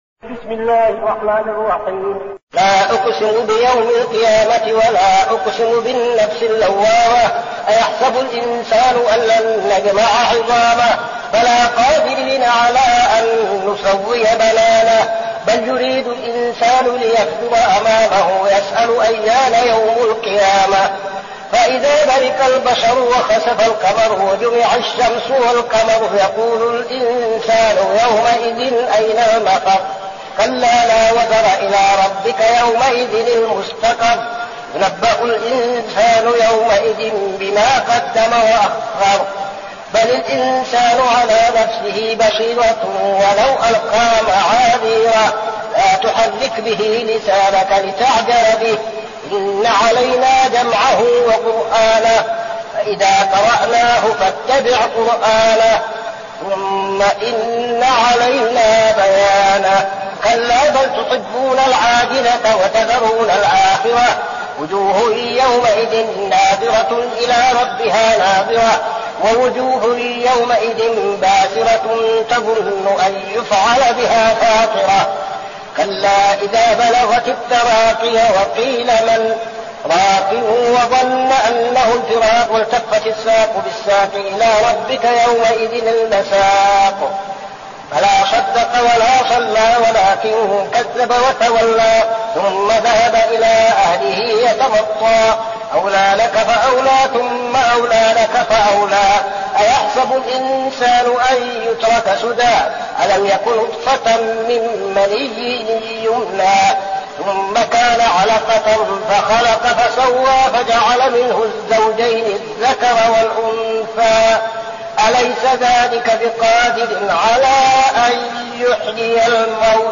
المكان: المسجد النبوي الشيخ: فضيلة الشيخ عبدالعزيز بن صالح فضيلة الشيخ عبدالعزيز بن صالح القيامة